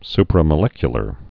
(sprə-mə-lĕkyə-lər)